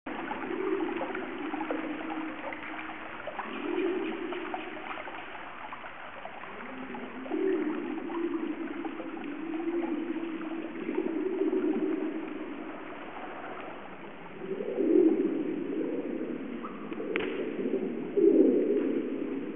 bruit_eau.wav